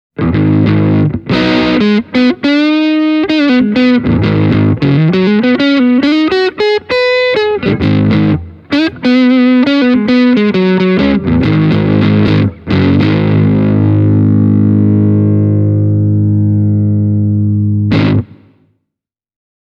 Ääniesimerkit on äänitetty Zoom H1 -tallentimilla.
Kaikki kolme ääniesimerkkiä on soitettu Les Paul -tyylisellä kitaralla:
Yamaha THR10C – British Blues + Hall